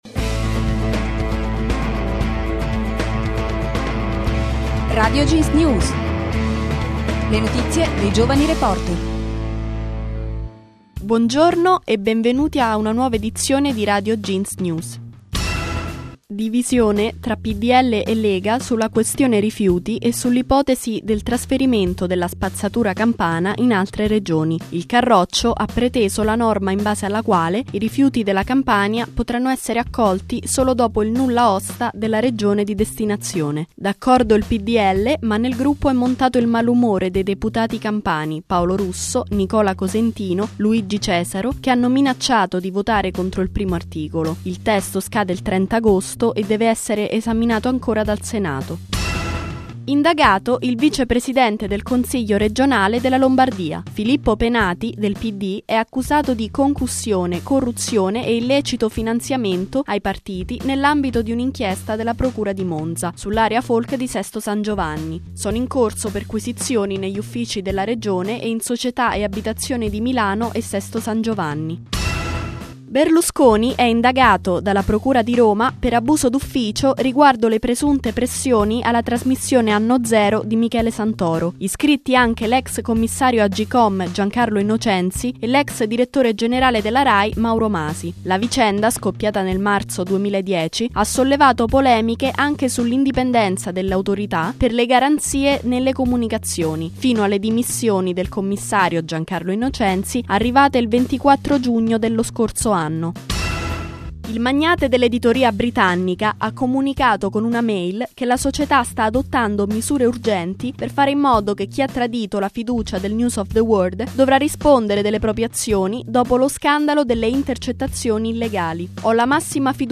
Le notizie dei giovani reporter